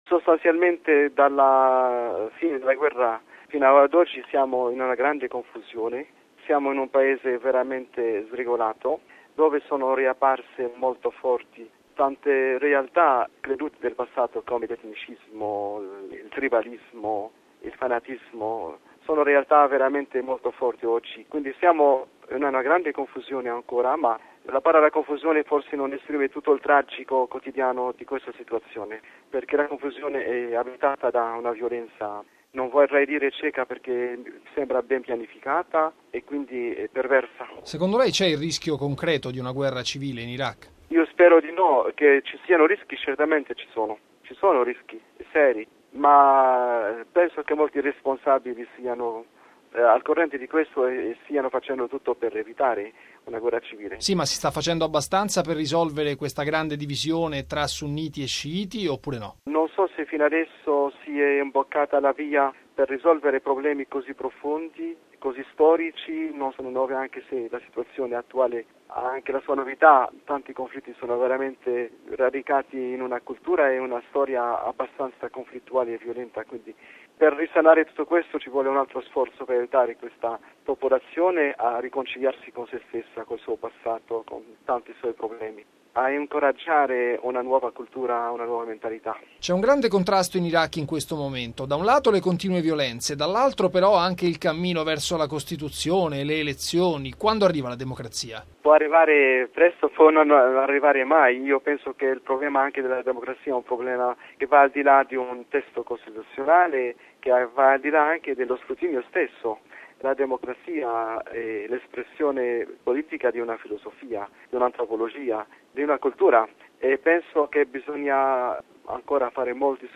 E la situazione nel Paese rimane drammatica, come conferma l’arcivescovo latino della capitale, Jean Benjamin Sleiman